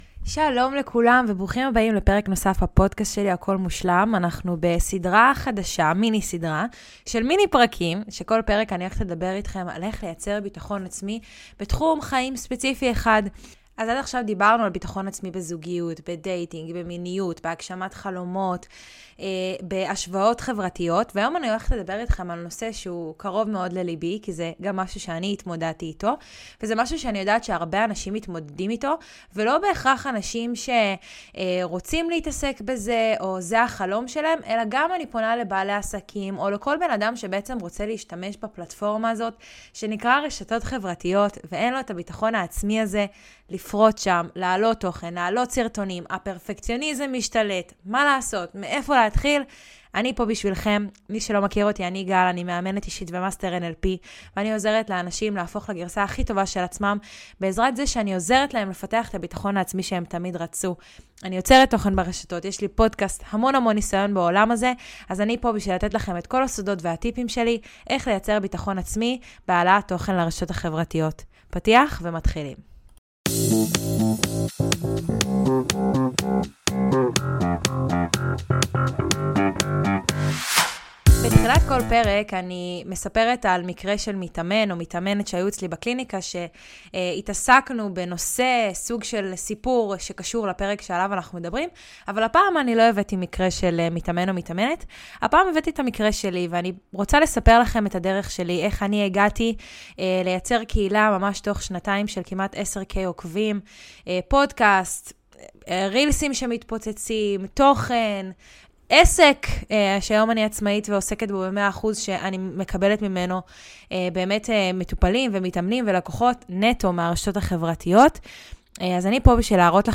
מיני עונה של פרקי סולו קצרים וממוקדים שיעזרו לכם לפתח ביטחון עצמי במקומות ספציפיים בחייכם!